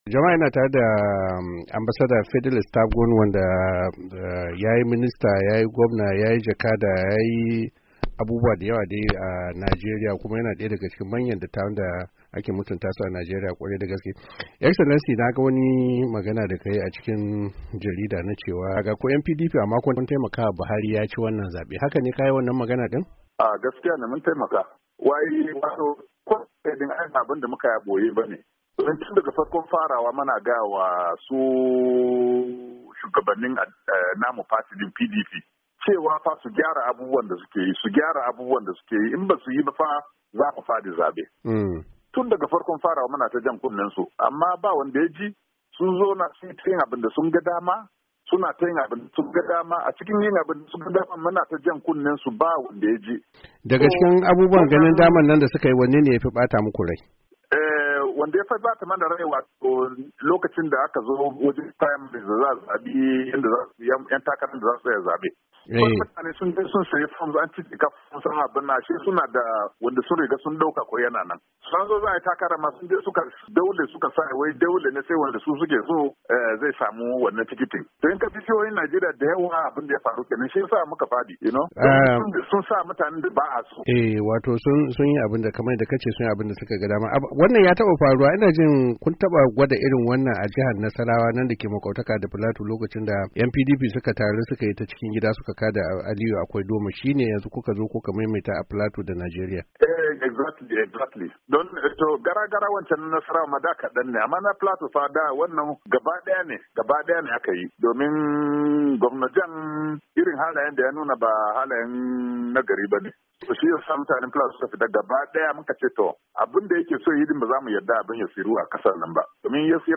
yayi hira da daya daga cikin ‘yayan jam’iyar PDP wadda ta sha kaye a babban zaben da ya gabata